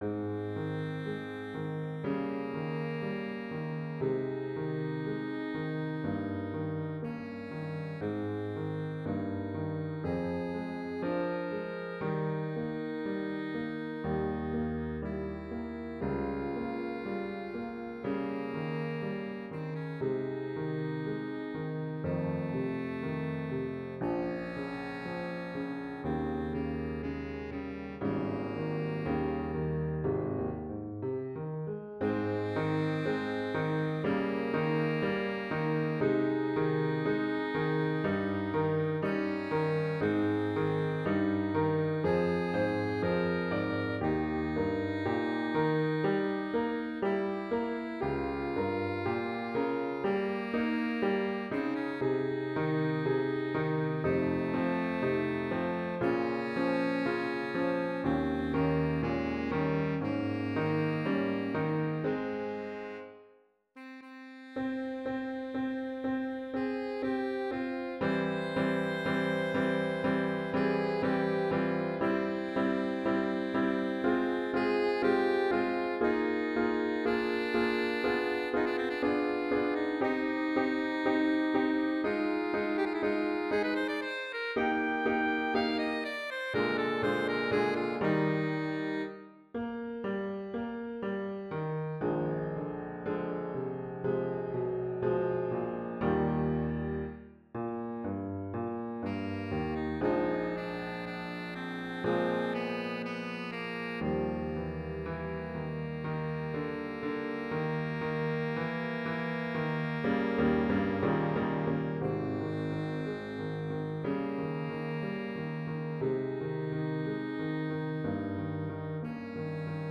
Voicing: Alto Saxophone and Piano